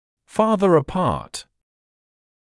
[‘fɑːðə ə’pɑːt][‘фаːзэ э’паːт]дальше друг от друга